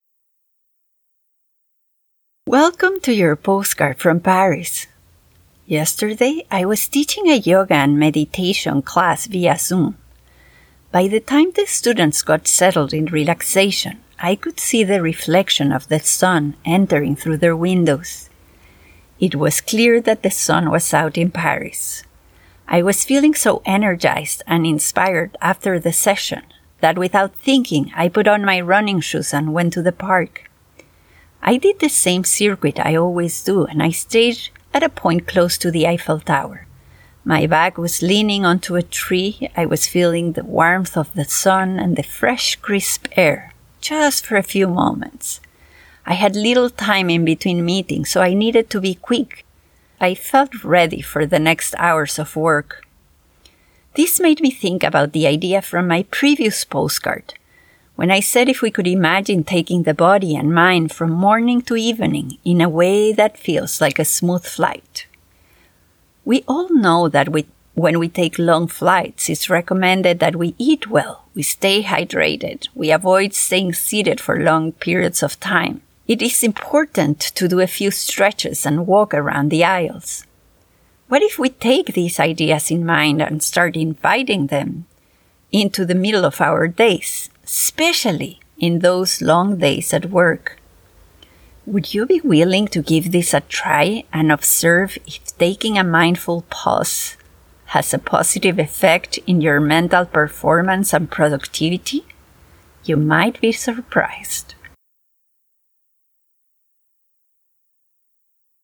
The sound of each postcard brings alive a particular moment of the every day life in Paris.